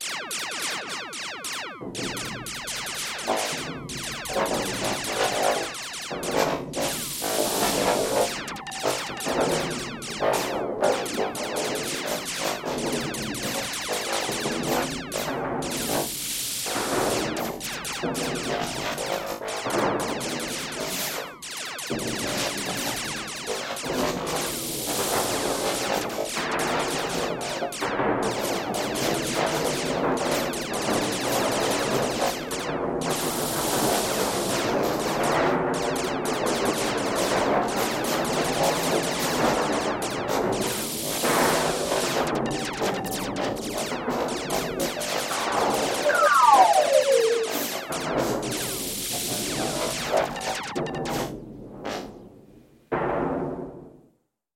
Звуки перестрелки инопланетян в космосе